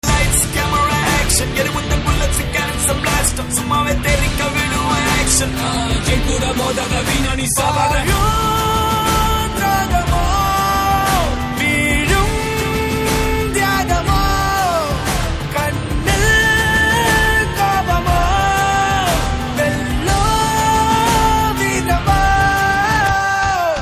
CategoryTamil Ringtones